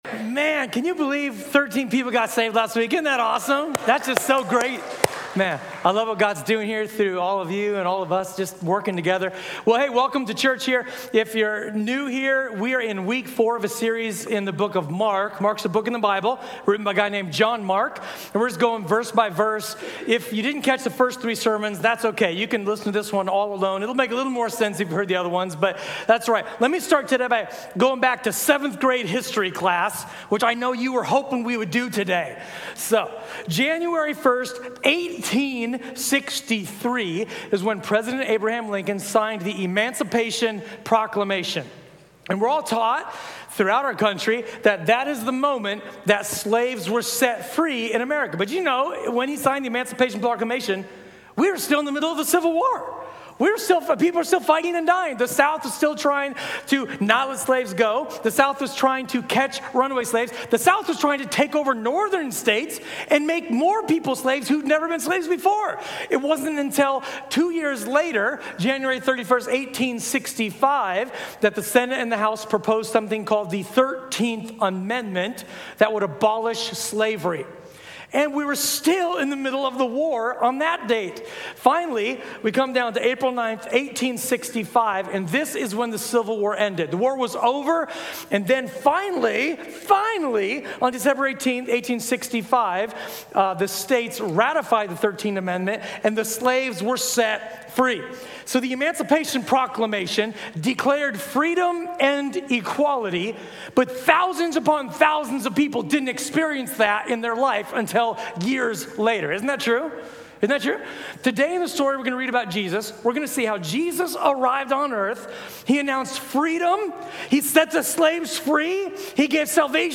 Sermon: “Recognizing the KING